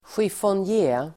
Ladda ner uttalet
chiffonjé substantiv, secretaire Uttal: [sjifånj'e:] Böjningar: chiffonjén, chiffonjéer Synonymer: stor byrå Definition: hög byrå med utfällbar klaff att skriva på (high bureau with a fold-out writing surface)